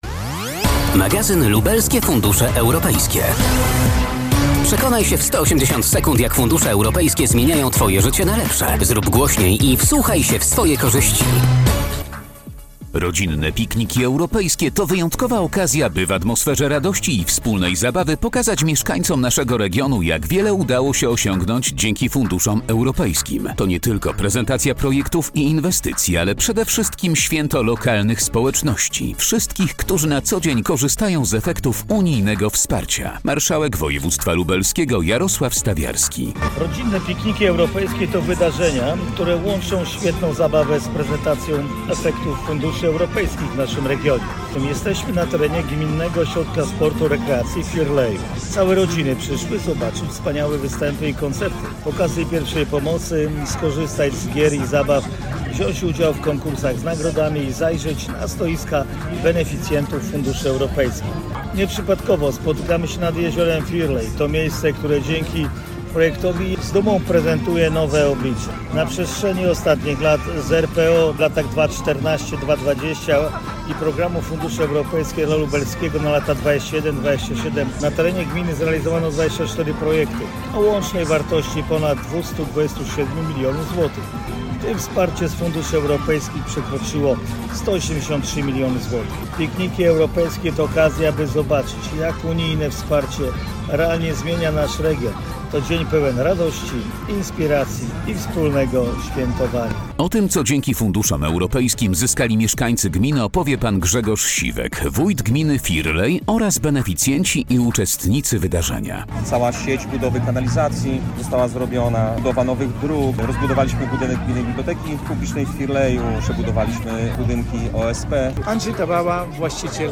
Audycja radiowa 180 sek. - nabory